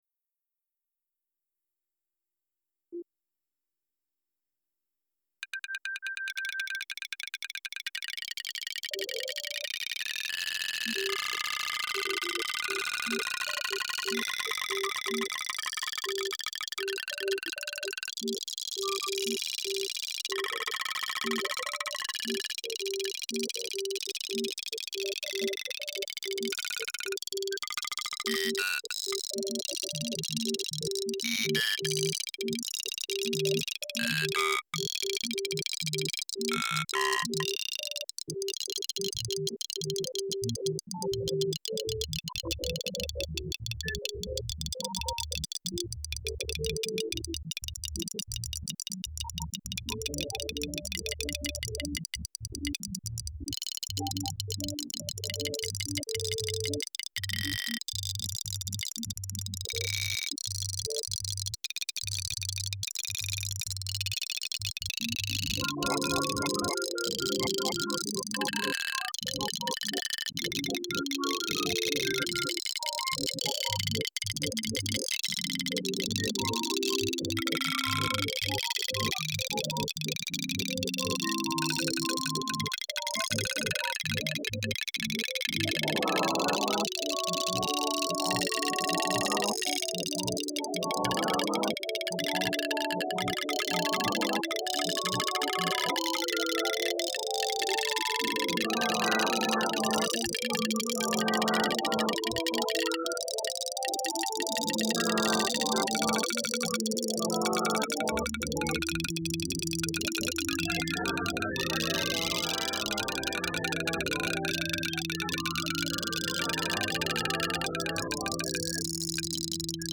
Animating ‘dropping while forming’ wooden alphabets. Technical flushes and bursts. And a childish joy for the usual segregated generative destruction.